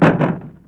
Index of /90_sSampleCDs/E-MU Producer Series Vol. 3 – Hollywood Sound Effects/Human & Animal/Falling Branches